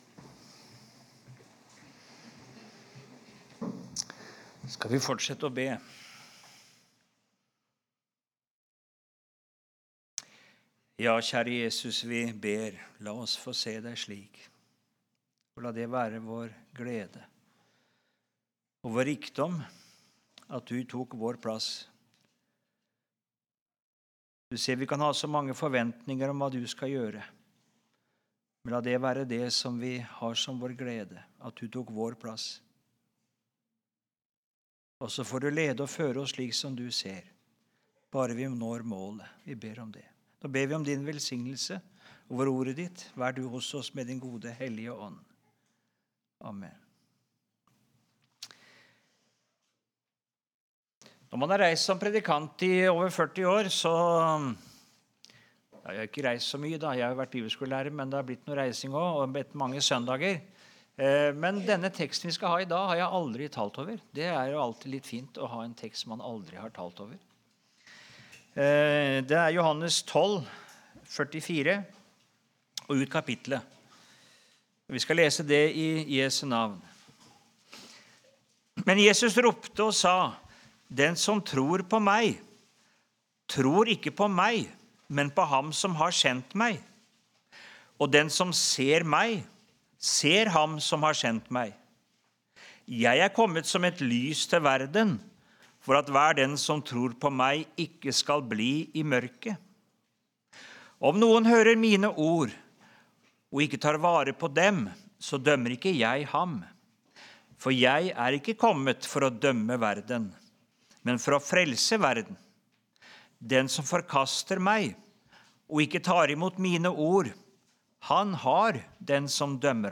09.11.2025 | Søndagsmøte på Fossnes